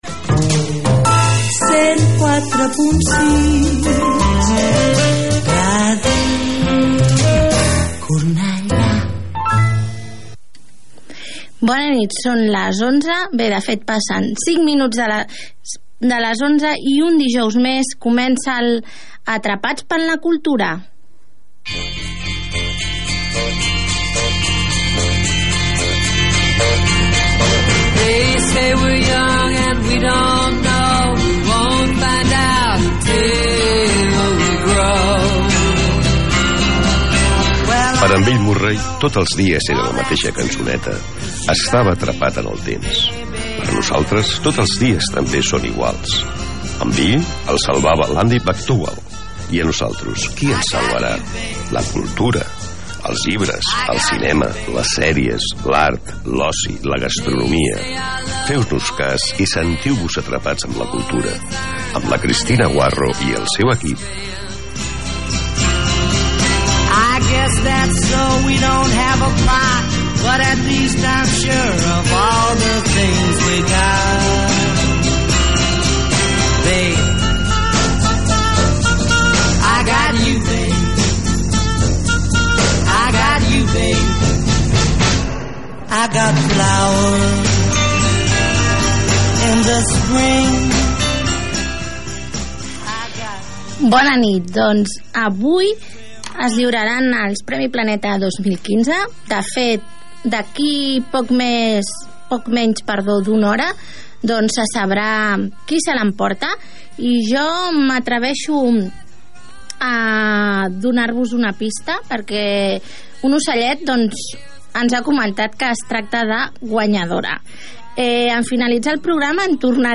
Indicatiu de la ràdio, salutació, careta del programa, el Premio Planeta 2015, tema musical
Gènere radiofònic Cultura